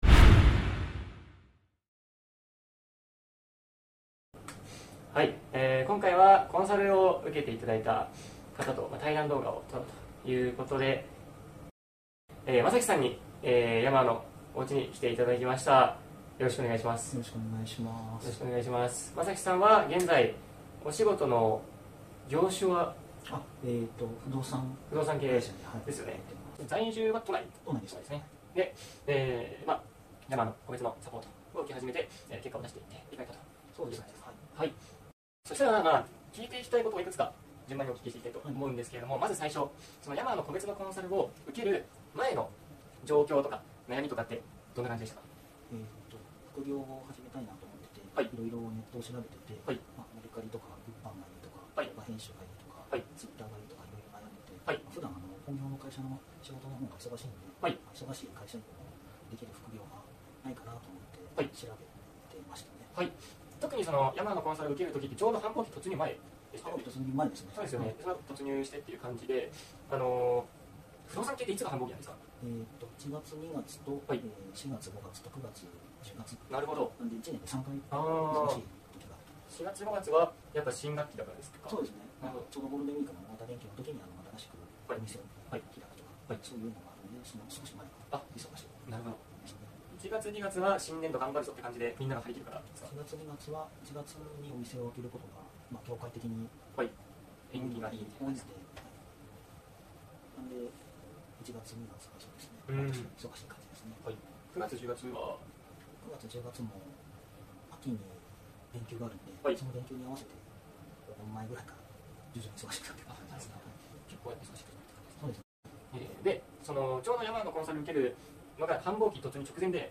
2023 テンポよく最後まで見れるように 1.5倍速に編集してありますので ぜひ見てください。